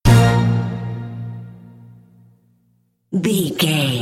Aeolian/Minor
B♭
drums
percussion
strings
conga
brass
hip hop
soul
Funk
neo soul
acid jazz
energetic
cheerful
vibrant
bouncy
Triumphant
funky